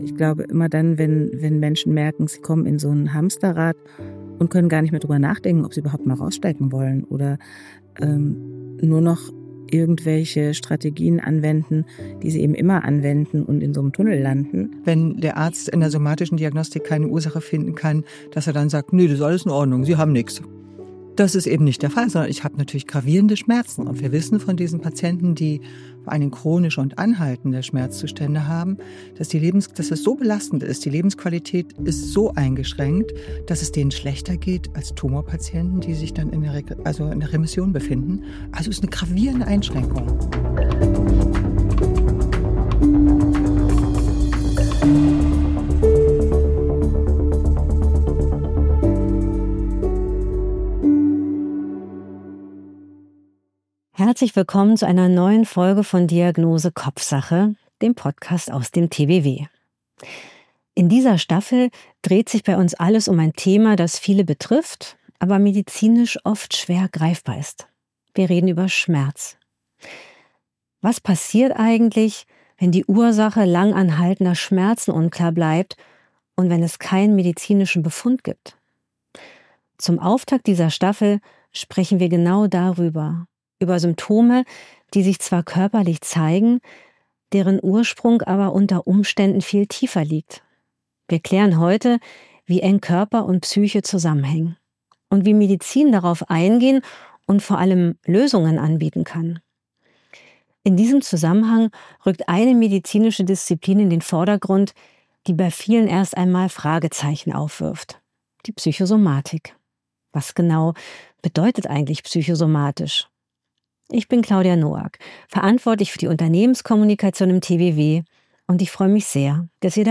Wir sprechen mit einem multidisziplinären Expert:innenkreis, um über Ursachen, Symptome und Behandlungsoptionen verschiedener psychischer und psychosomatischer Erkrankungen zu informieren.